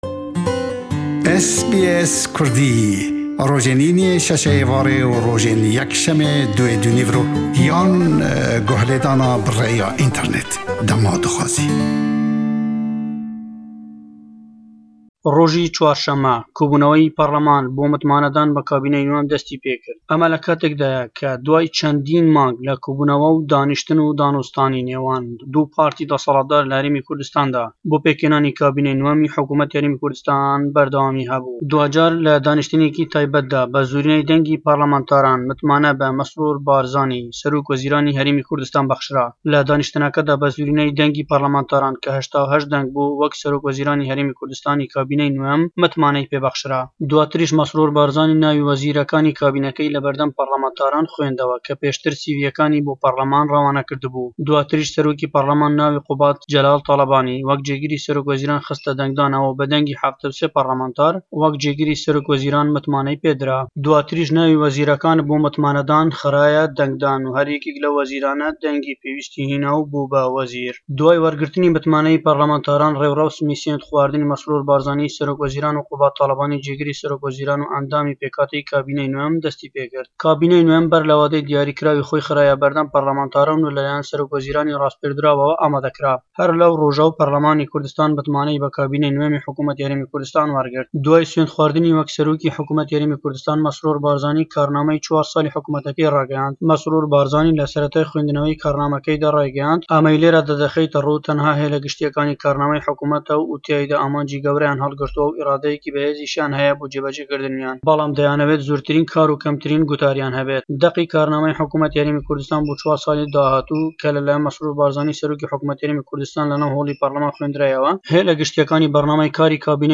Peyamnêr